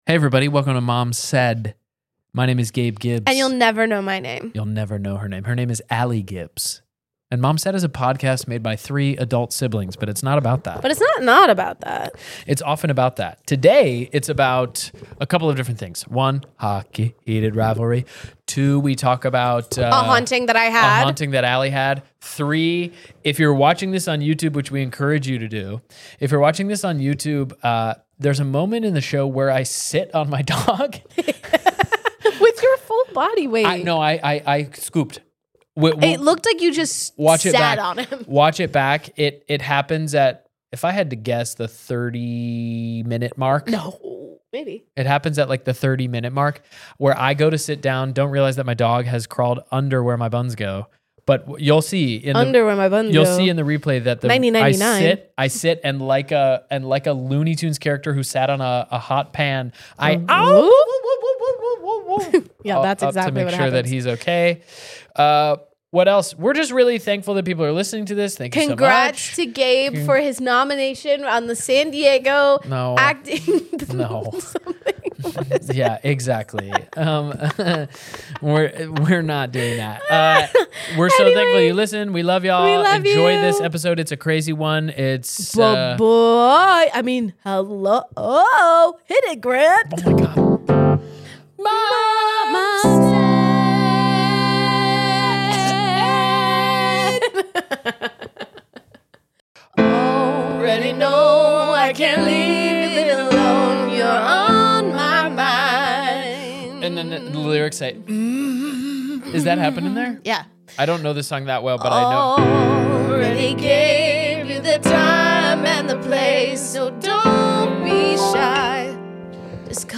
This week the siblings giggle about Hockey (heated rivalry ofc), hauntings and shoes on in the car!